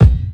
KICK_JUDY2.wav